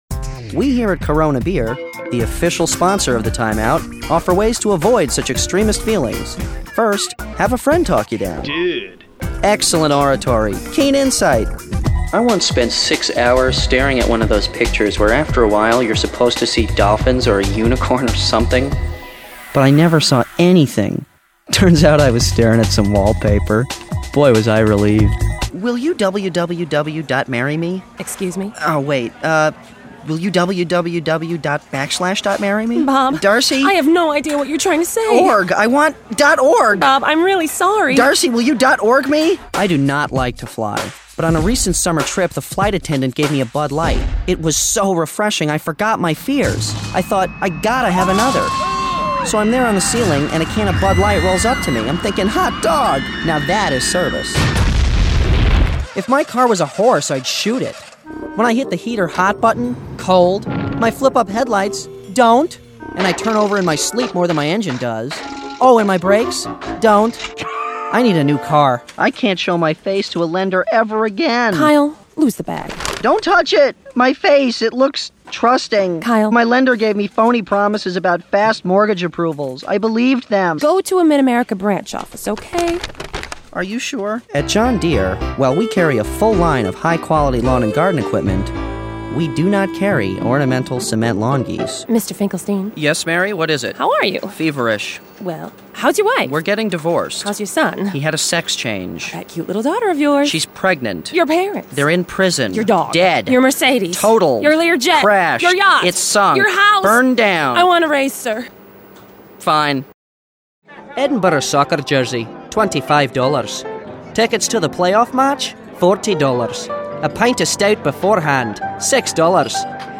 Voice Over My voice over acting demo.